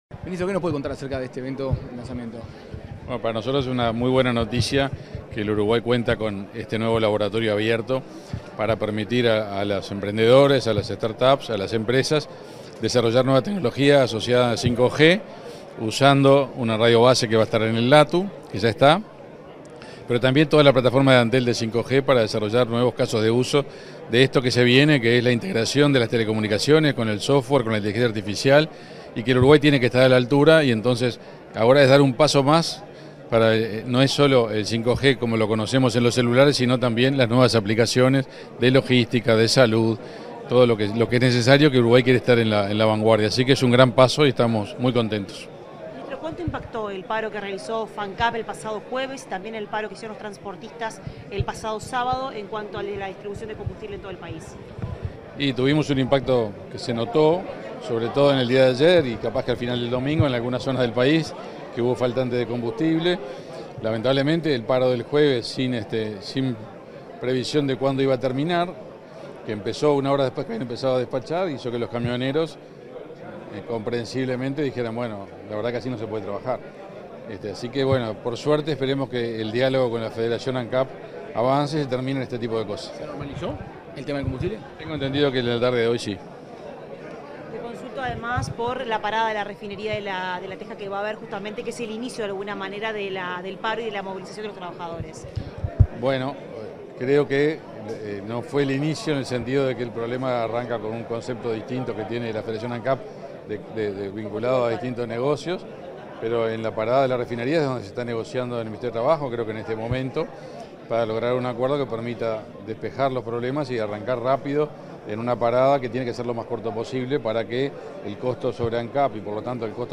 Declaraciones del ministro de Industria, Energía y Minería, Omar Paganini 17/10/2023 Compartir Facebook X Copiar enlace WhatsApp LinkedIn Luego de participar en la presentación de Antel Open Digital Lab, este 17 de octubre, el ministro de Industria, Energía y Minería, Omar Paganini, realizó declaraciones a la prensa.